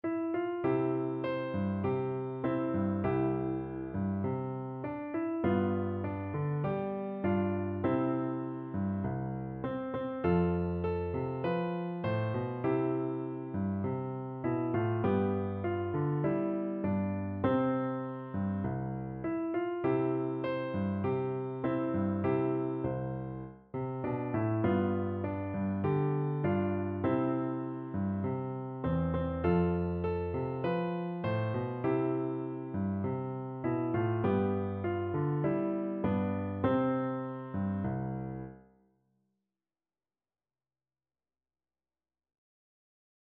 No parts available for this pieces as it is for solo piano.
Moderato
4/4 (View more 4/4 Music)